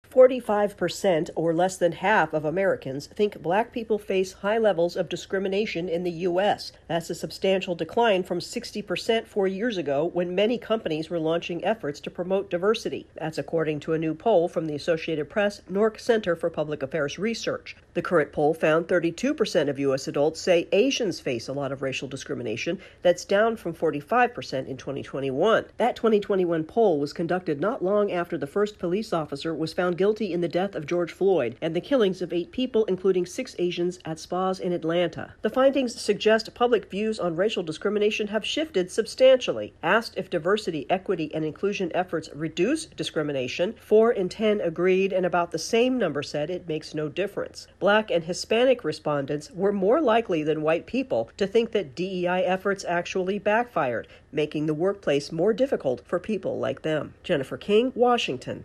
A new poll finds opinions on racial discrimination in America have changed in the past four years. AP correspondent